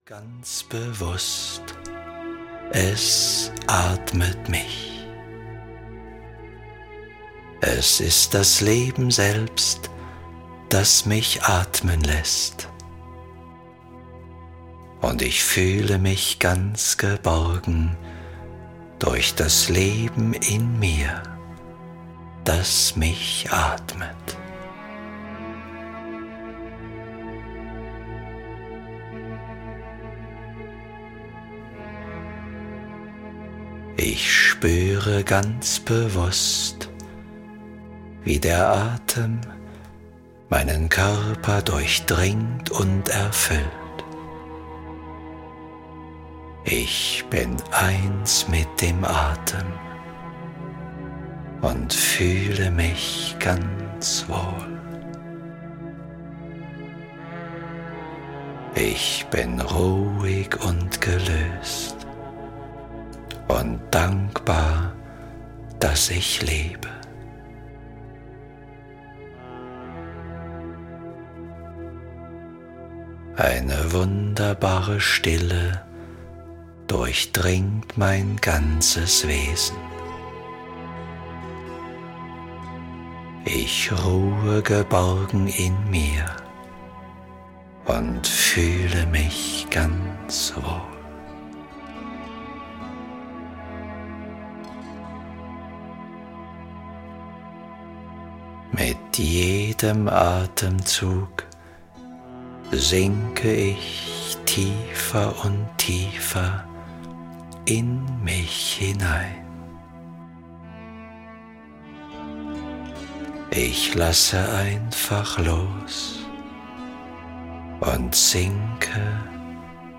Mental Coach: Zur Spitzenleistung - Kurt Tepperwein - Hörbuch